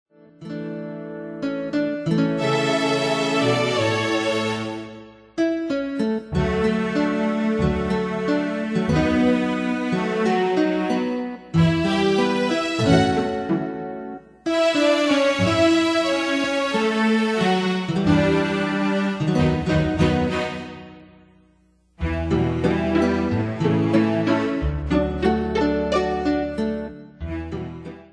Bandes et Trames Sonores Professionnelles
Pro Backing Tracks